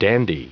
Prononciation du mot dandy en anglais (fichier audio)